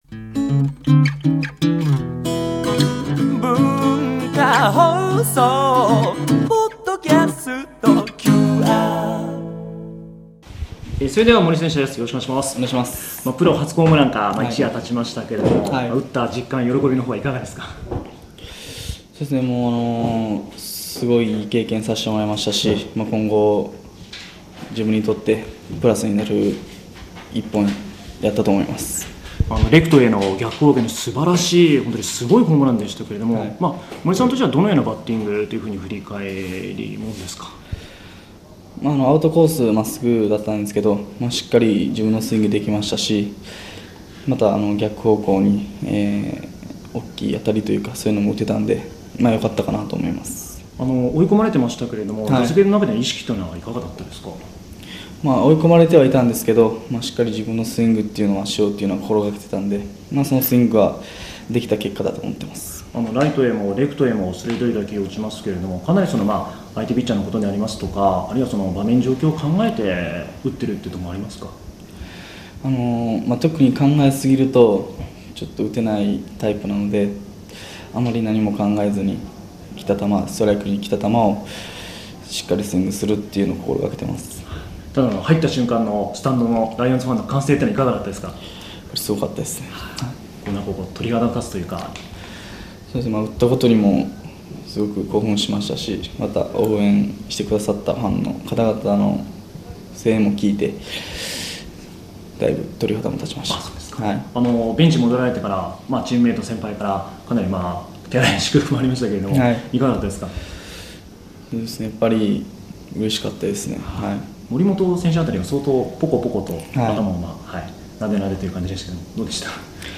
１５日の練習前にお話を伺いました。
森友哉選手インタビュー